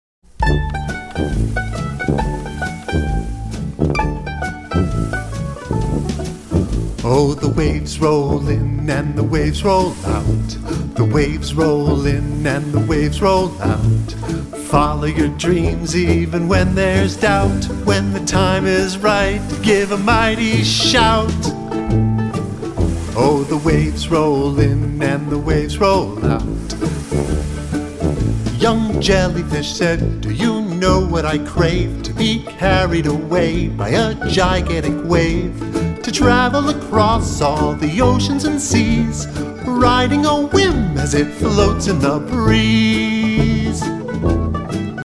With a youthful, warm and energetic style